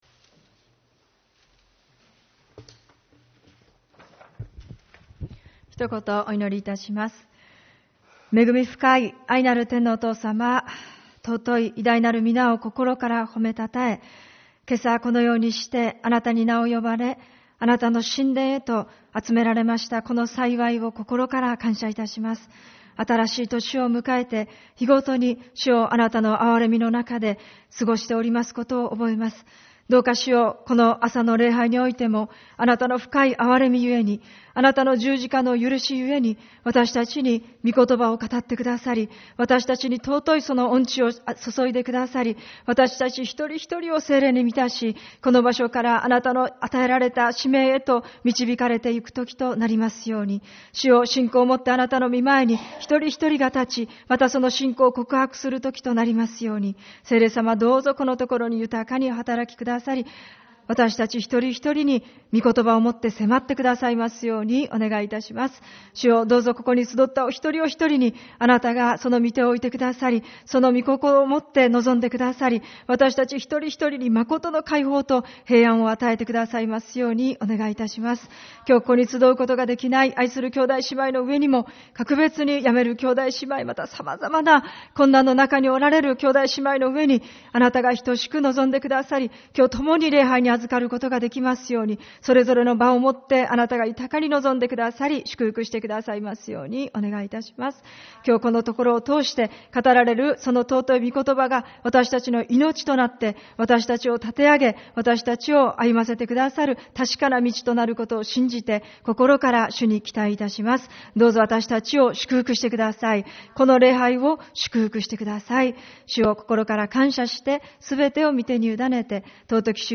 主日礼拝 「すべての人の祈りの家」